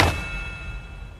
King Bradley's Footstep.wav